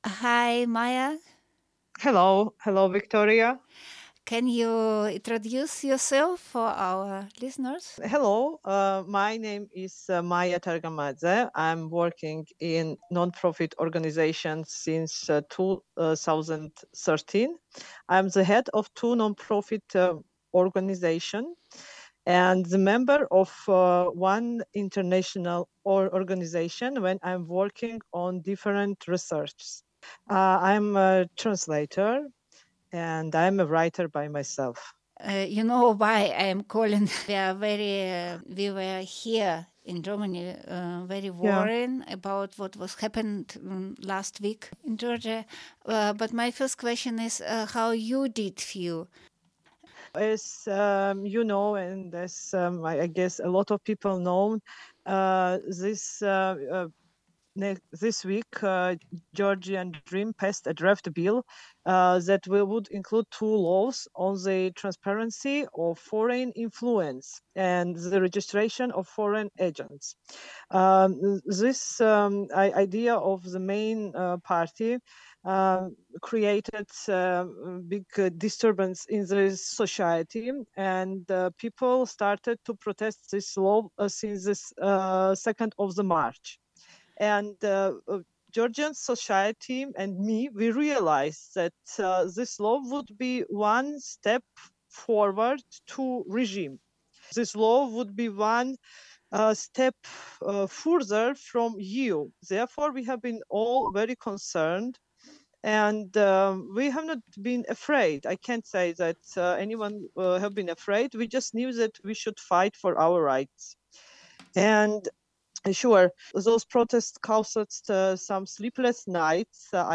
Rede & Aufruf Feministische Aktion 8. März: 7:14
Grußworte Feminism Unstoppable München: 0:53